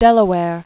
(dĕlə wâr, wər), Baron.